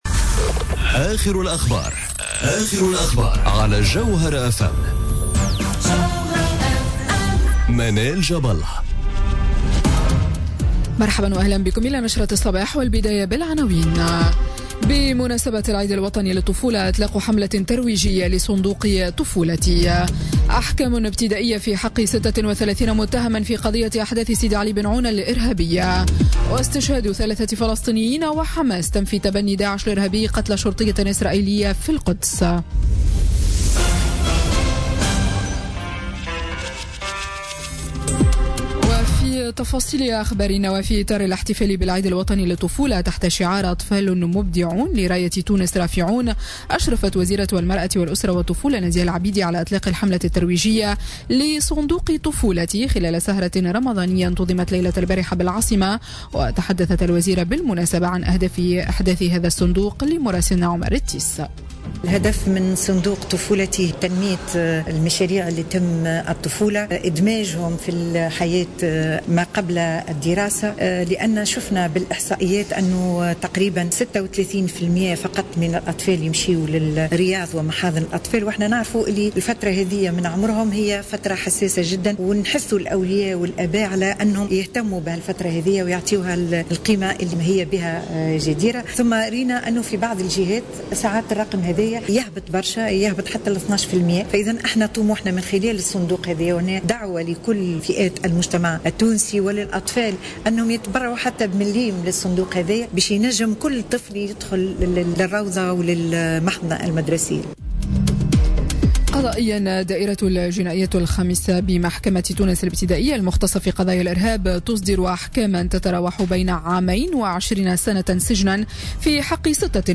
نشرة أخبار السابعة صباحا ليوم السبت 17 جوان 2017